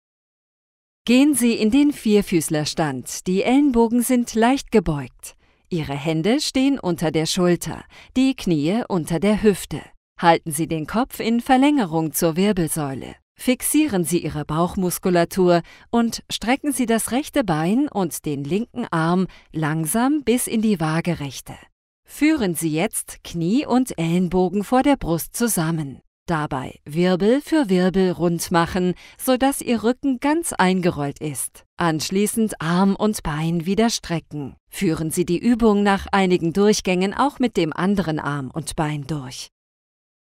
E-learning
• Mikrofon: Neumann TLM 67 / Neumann TLM 103
• Acoustic Cabin : Studiobricks ONE
ContraltoMezzo-Soprano
ConfidentDynamicExperiencedFriendlyReliableTrustworthyVersatileYoungWarm